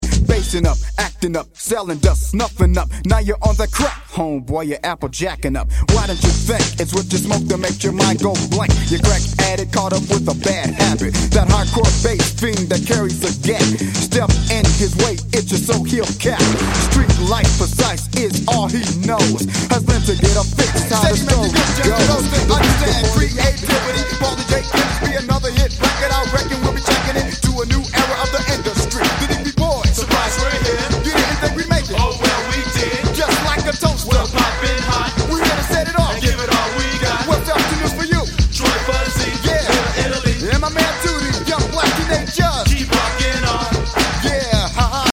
gangsta rap